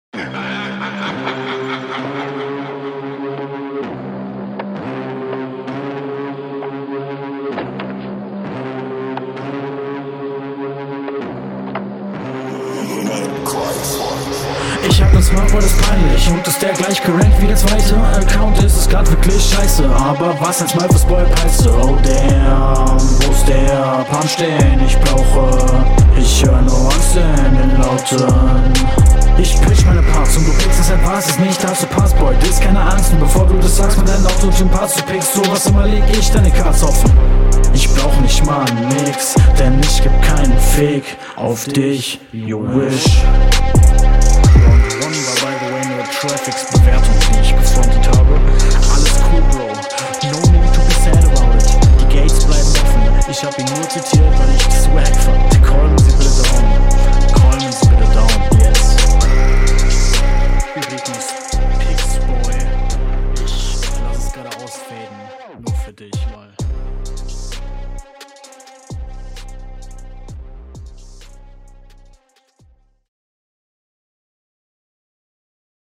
Flow: Flowst echt gut über den Beat und die Variation bei "Oh damn" fand ich …